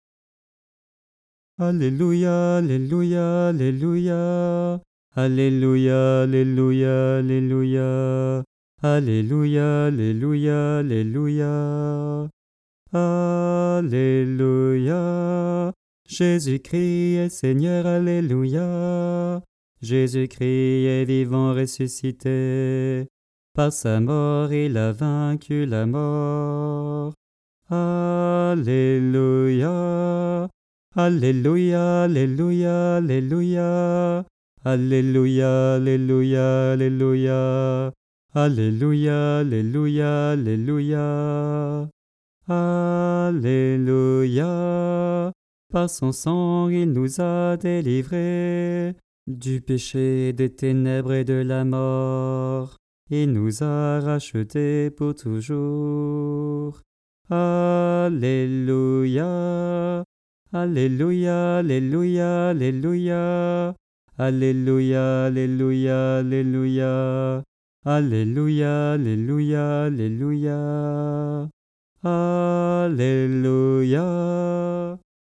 Tradition polonaise
Voix chantée (MP3)COUPLET/REFRAIN
BASSE